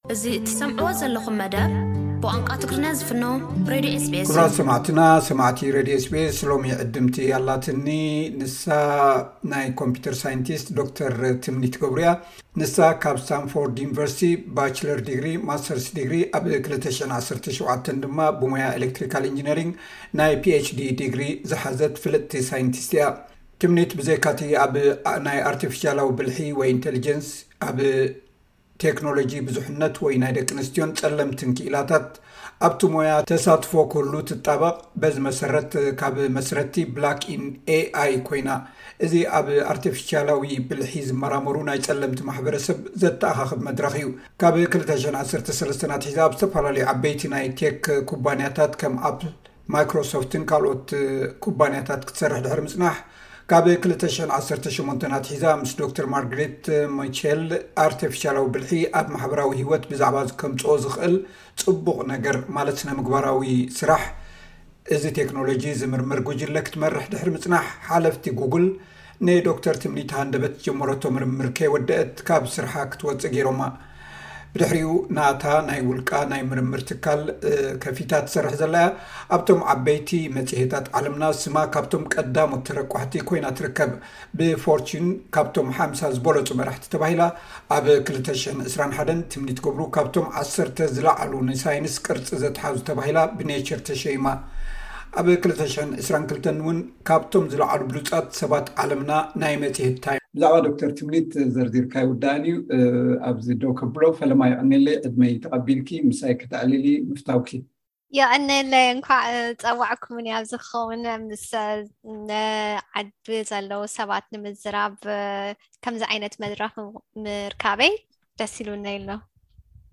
ዕላል ምስ ሳይንቲስት ኣርተፊሻላዊ ብልሒ ዶ/ር ትምኒት ገብሩ